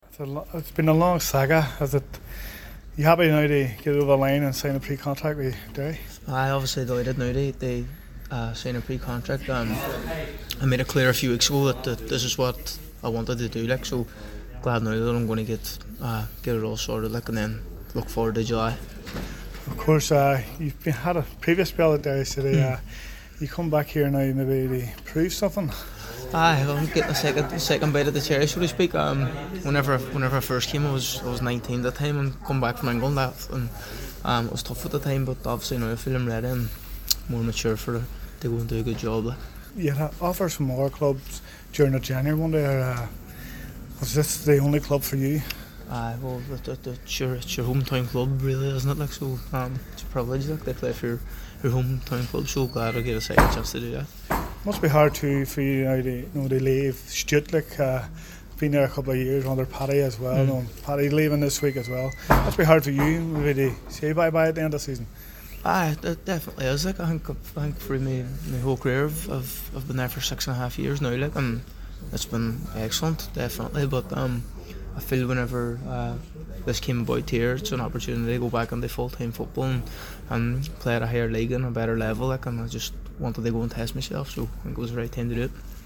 press conference.